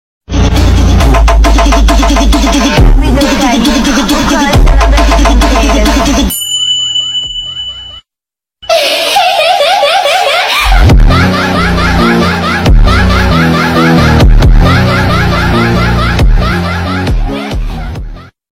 EDIT: I JUST REALIZED THE AUDIO SOUNDS LIKE A BAD QUALITY MIC WHAT IS THAT